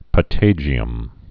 (pə-tājē-əm)